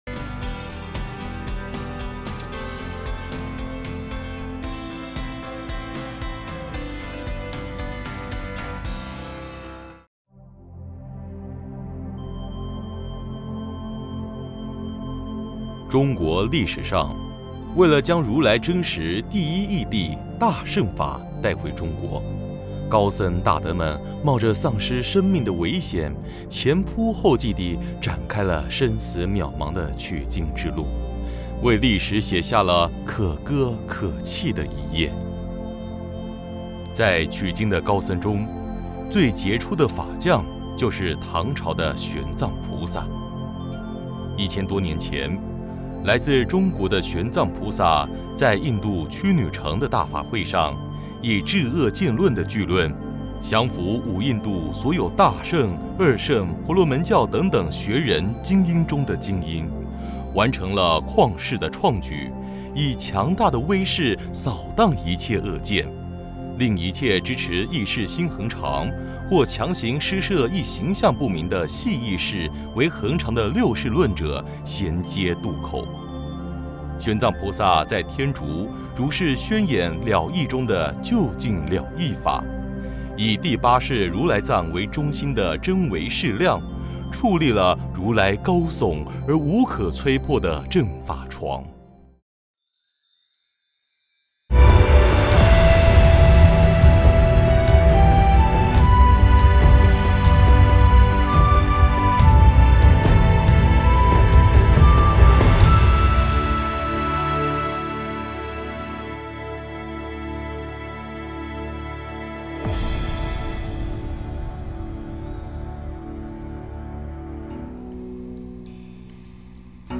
专题视频，正觉同修会影音，同修会音频，同修会视频